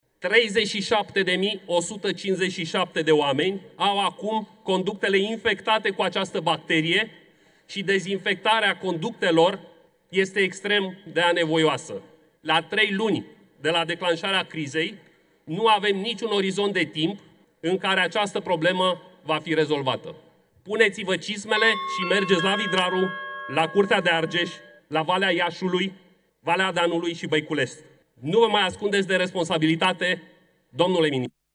Deputatul USR Radu Mihaiu, la dezbaterea din plen privind situația apei potabile din Argeș: „Puneți-vă cizmele și mergeți la Vidraru, domnule ministru”.
Puneți-vă cizmele și mergeți la Vidraru, domnule ministru! – este îndemnul pe care deputatul USR Radu Mihaiu i l-a adresat în plenul Camerei ministrului Energiei, Bogdan Ivan.